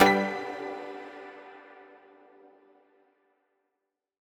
menu-multiplayer-click.mp3